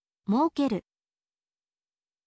moukeru